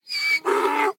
Minecraft Version Minecraft Version snapshot Latest Release | Latest Snapshot snapshot / assets / minecraft / sounds / mob / horse / donkey / idle3.ogg Compare With Compare With Latest Release | Latest Snapshot